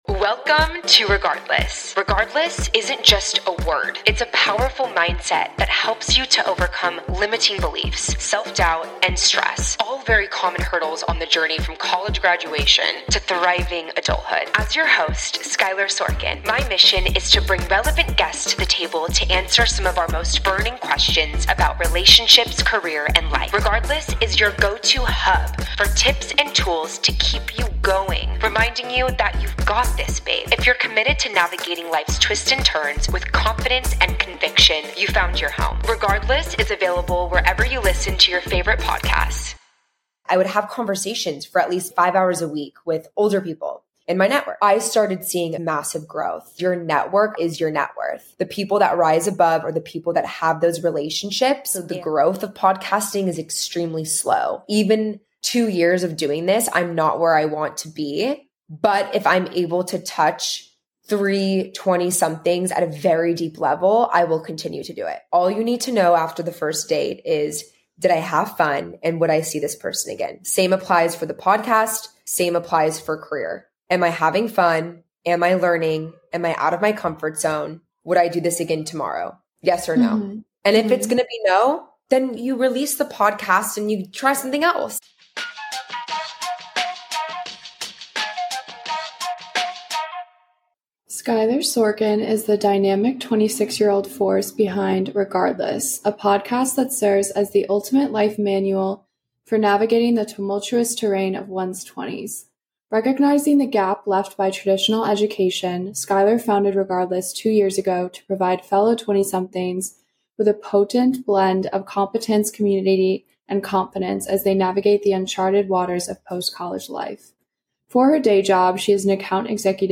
Navigating Your 20s and Developing a Side Hustle: A Conversation Between 2 Podcasters